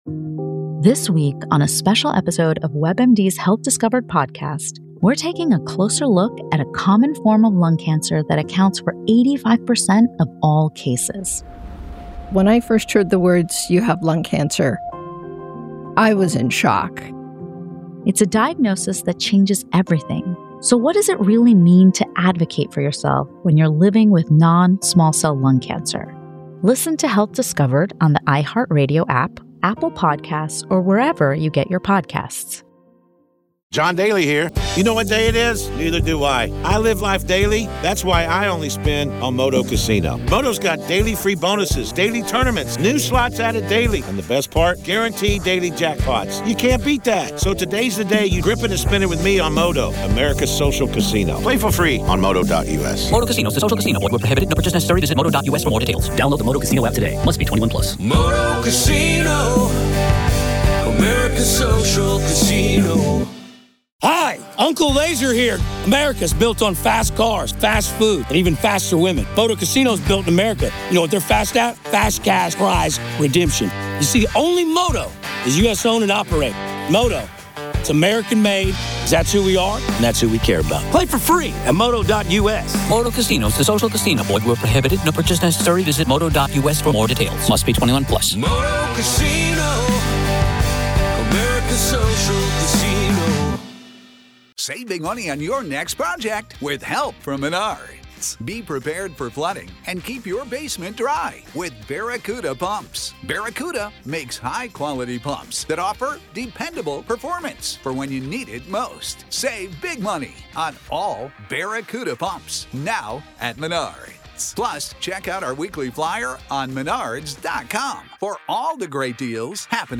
This conversation dives into the unsettling space where faith, fear, and the possibility of demonic influence intersect.